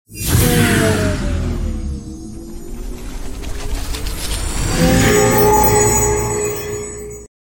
Teleport Sound FX (NO Copyright)